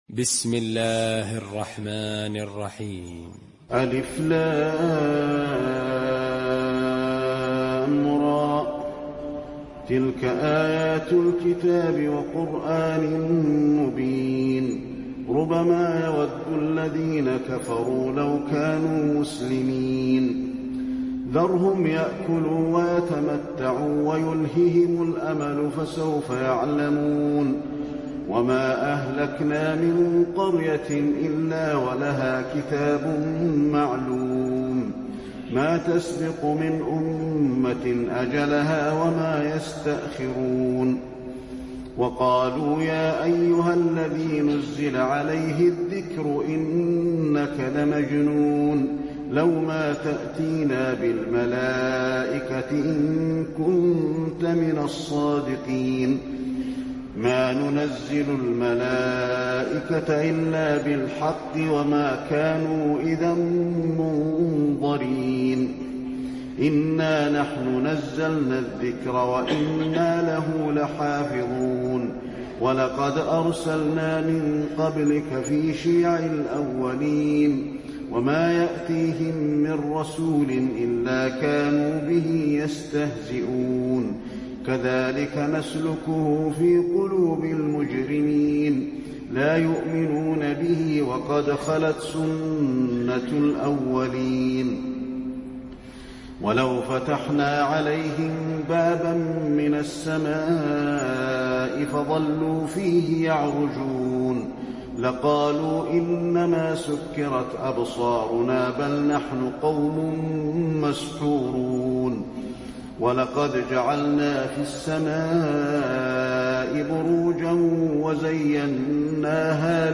المكان: المسجد النبوي الحجر